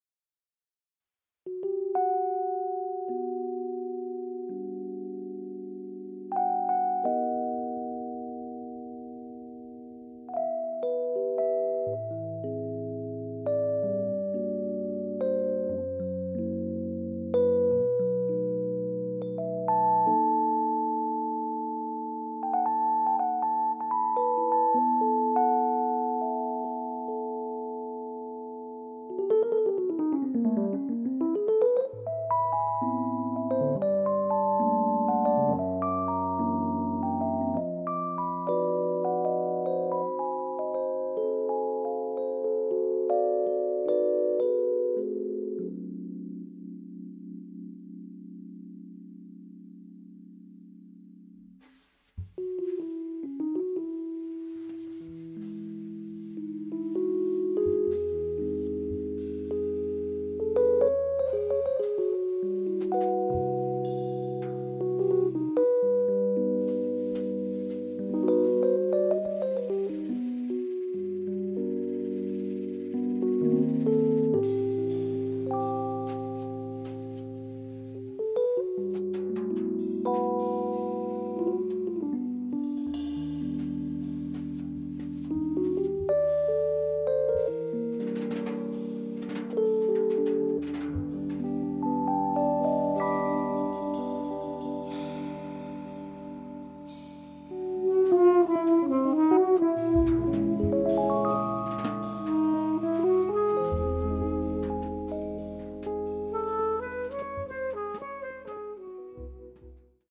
sax
guitar
drums
Fender Rhodes
bass